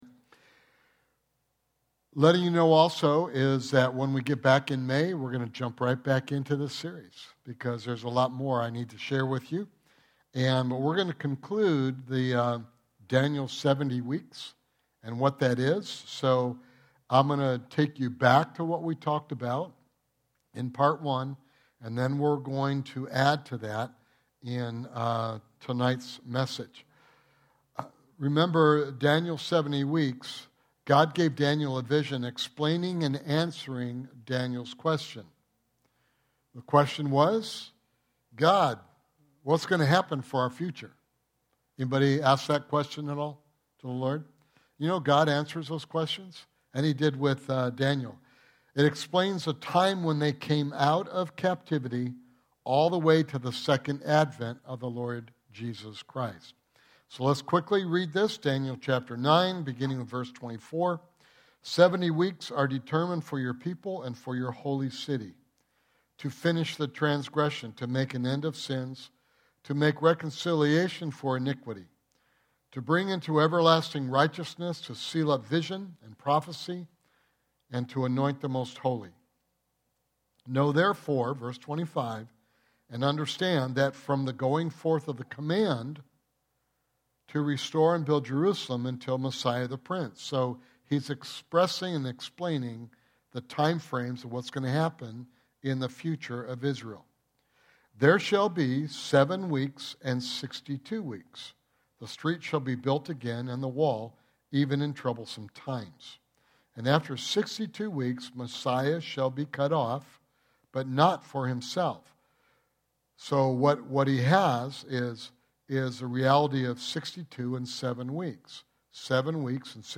Sermon Series
Sunday evening Bible study